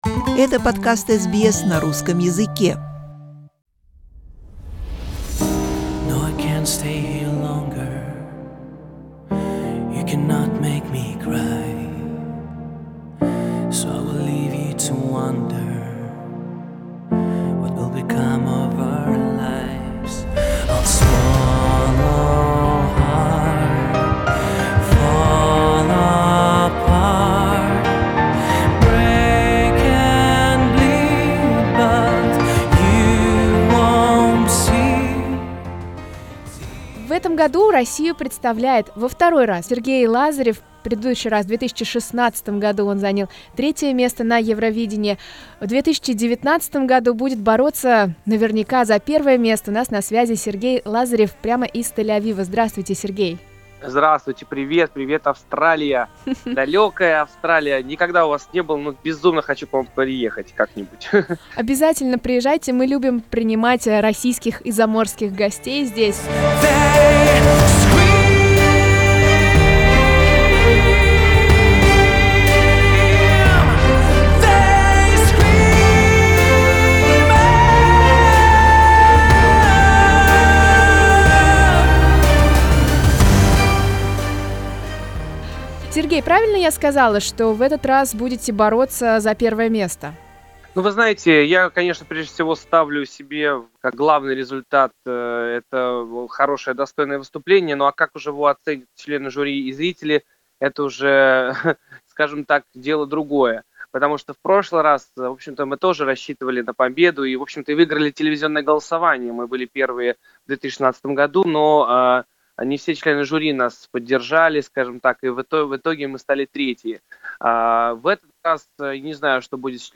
В этом году, в Тель-Авиве, исполнитель представляет песню 'Scream' ('Крик'), называя ее более зрелой и смелой по сравнению с предыдущей. Мы созвонились с Сергеем в его перерыве между репетициями к полуфиналу.